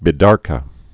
(bī-därkə)